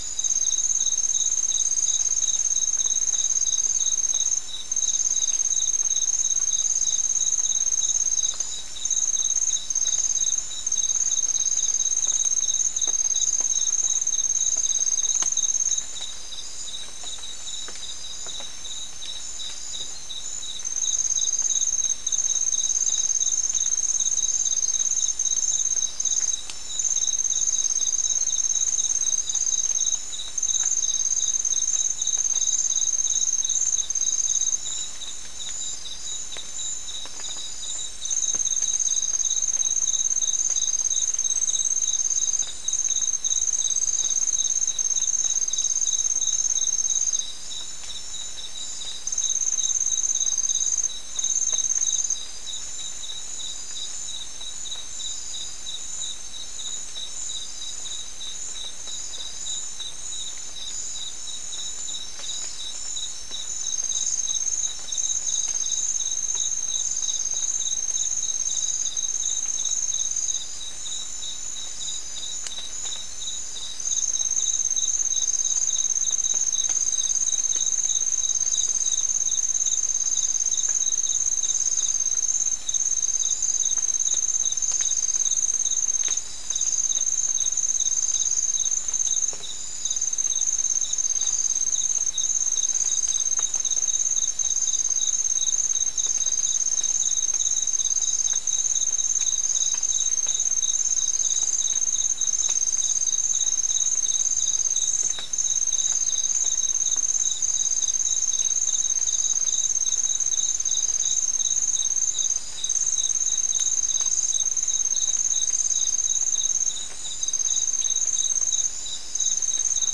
Soundscape Recording Location: South America: Guyana: Mill Site: 3
Recorder: SM3